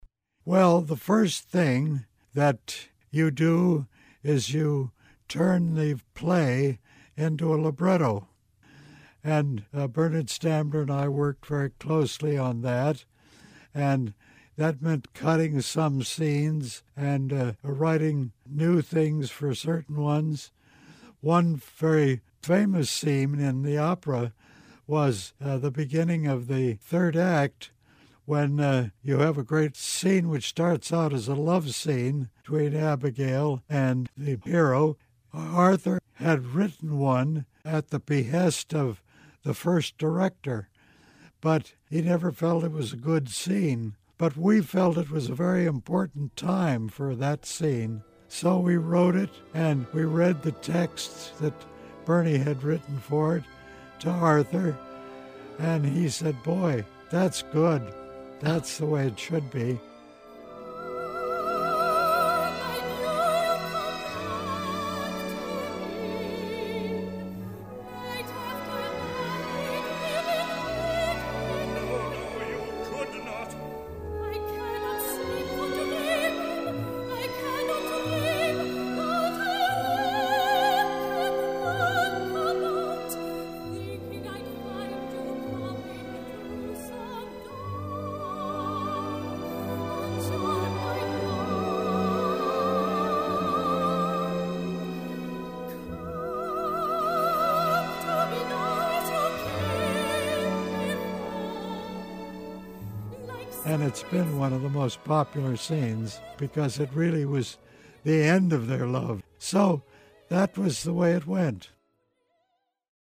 Excerpts from The Crucible, performed by New York City Opera, conducted by Emerson Buckley, and used courtesy of Albany Records.